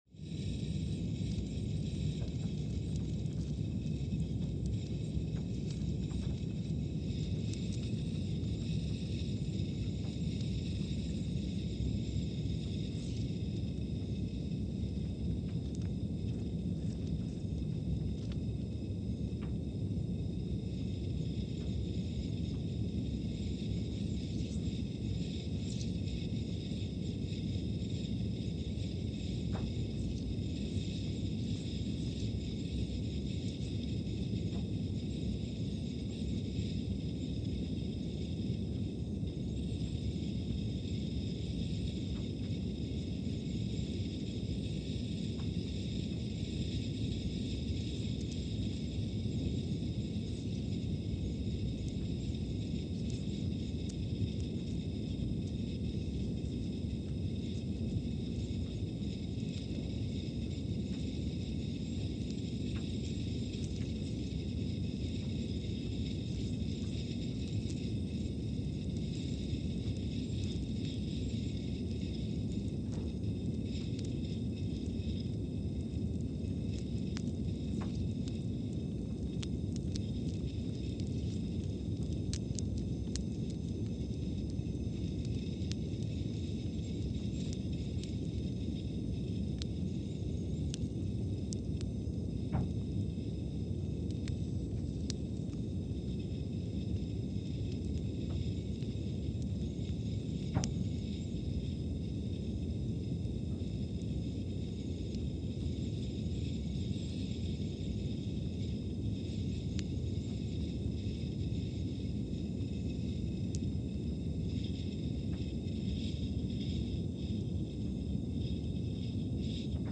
Scott Base, Antarctica (seismic) archived on December 6, 2020
Sensor : CMG3-T
Speedup : ×500 (transposed up about 9 octaves)
Loop duration (audio) : 05:45 (stereo)
Gain correction : 25dB